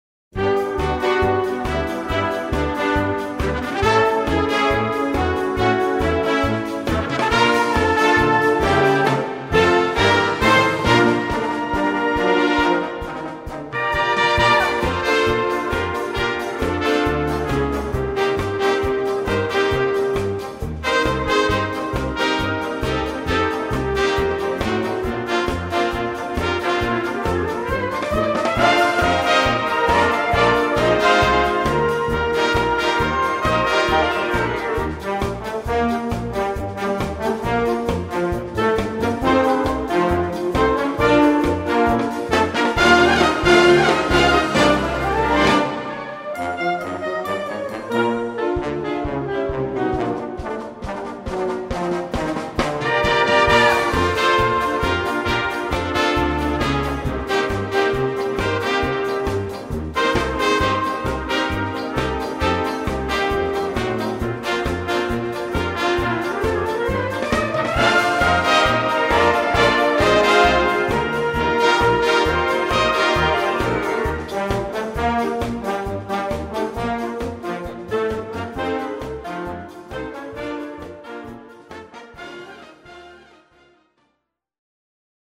Gattung: Walzer
Besetzung: Blasorchester
Global-Kryner-Style.